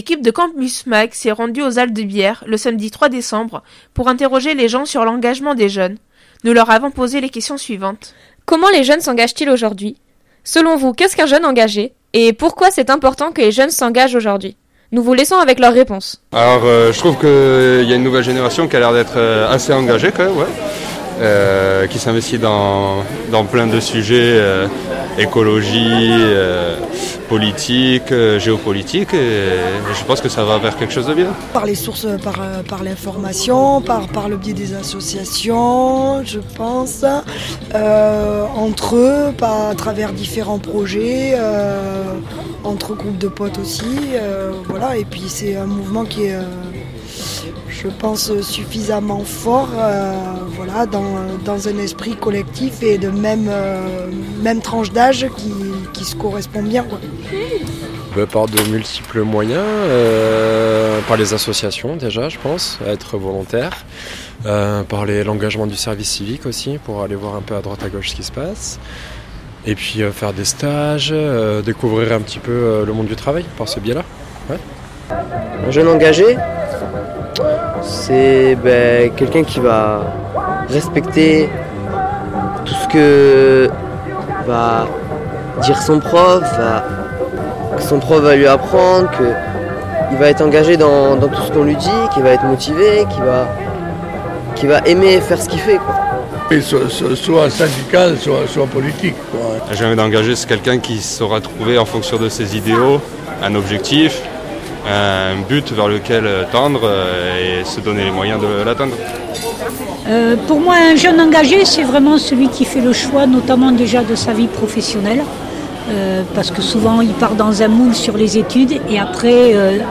Micro-Trottoir sur l'engagement
Retrouvez les différents témoignages dans ce court micro-trottoir de 3 minutes.